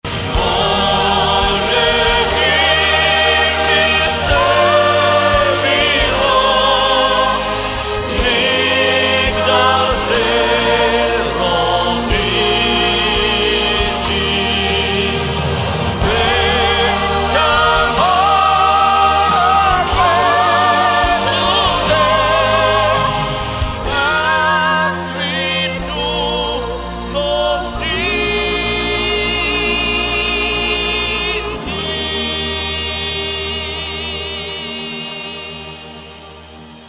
music: Traditional
key: Db-major/Eb-major